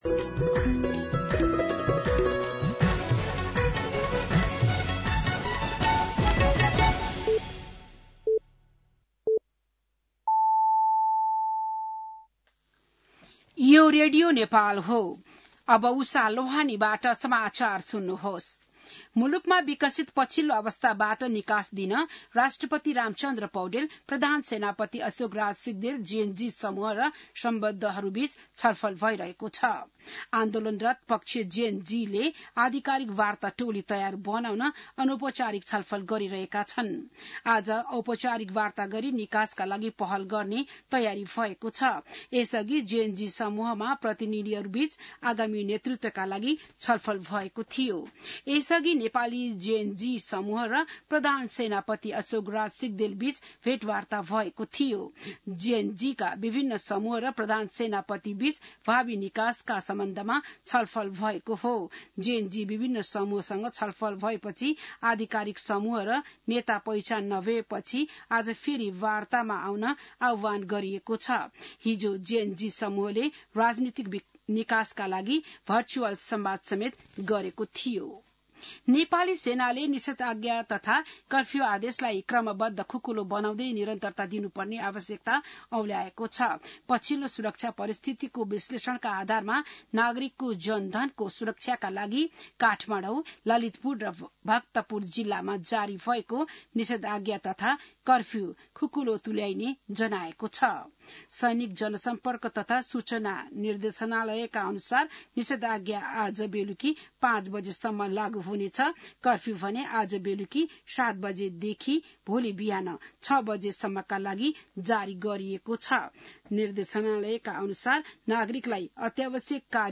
बिहान ११ बजेको नेपाली समाचार : २६ भदौ , २०८२